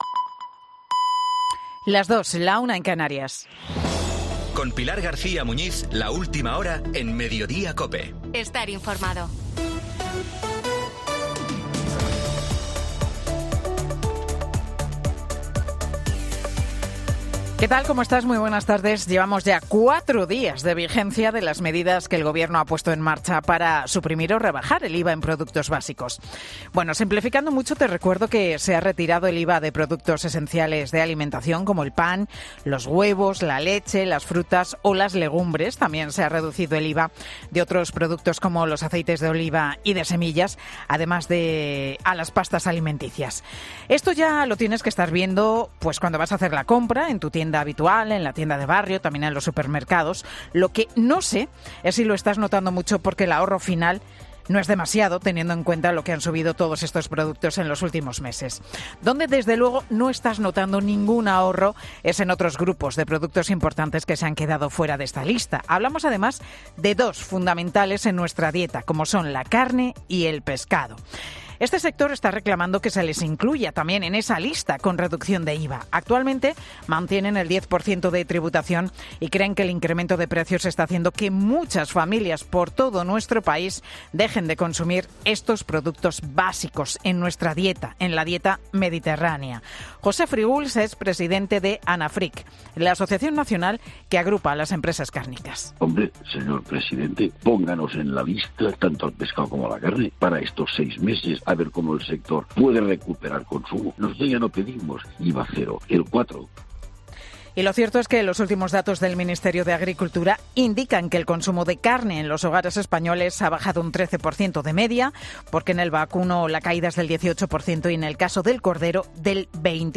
AUDIO: Escucha el monólogo de Pilar García Muñiz en 'Mediodía COPE'